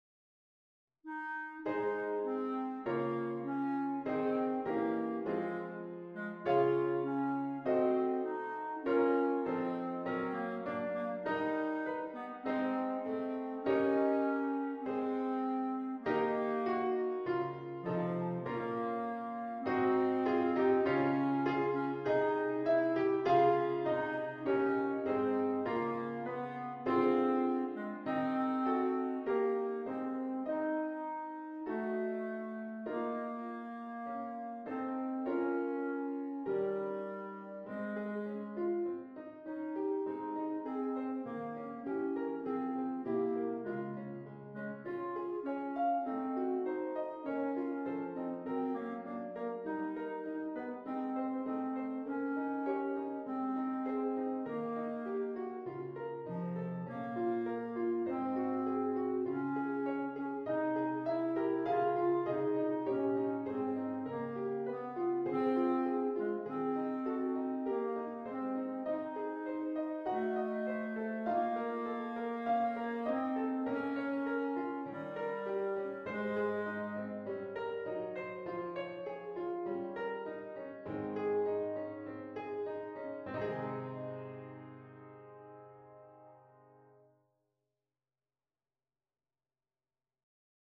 This file contains the performance, accompaniment, and sheet music for Bb Clarinet.